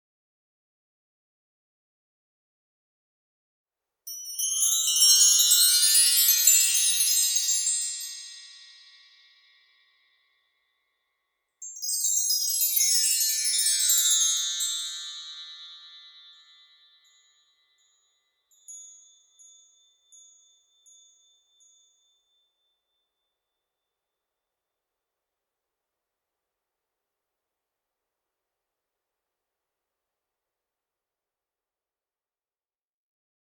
Die 27 Klangstäbe erzeugen beim Spielen ein wunderbares Glissando, das dem Klang einer Harfe ähnelt, aber etwas metallischer klingt. Die sanften Töne helfen, den Geist zu beruhigen und den Körper zu entspannen.